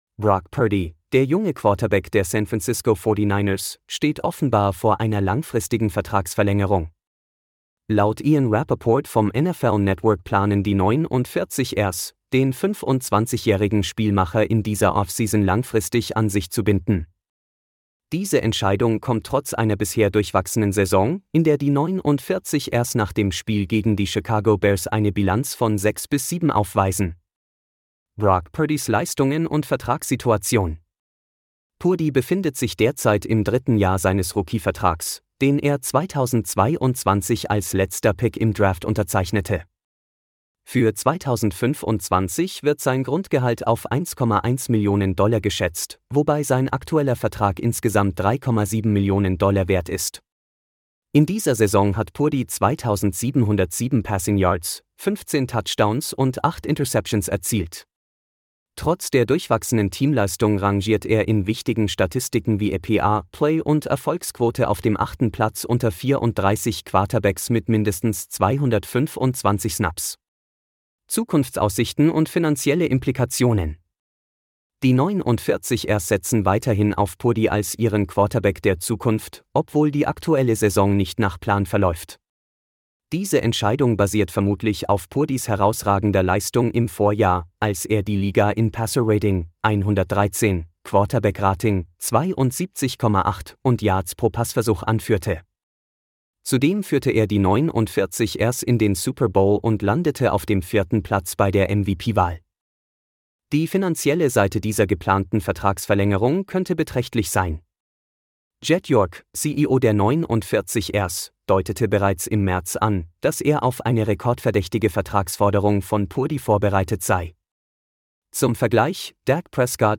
Diese Audioversion des Artikels wurde künstlich erzeugt und wird stetig weiterentwickelt.